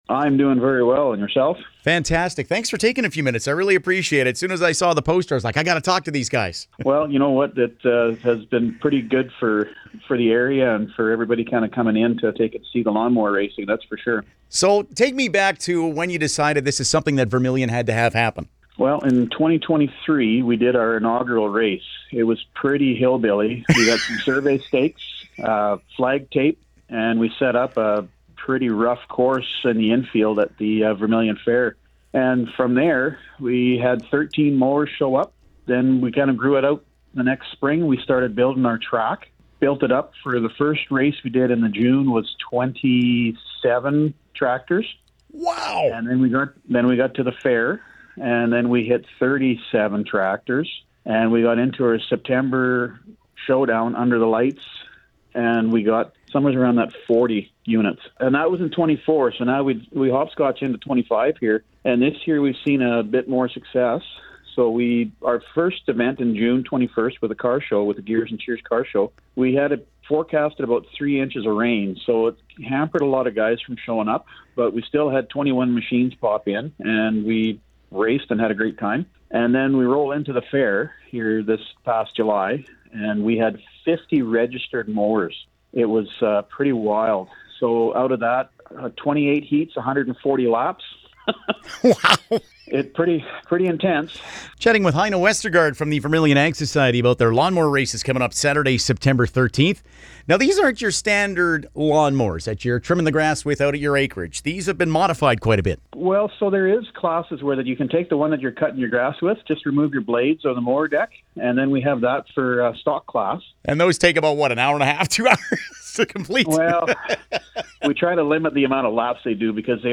lawn-mower-interview.mp3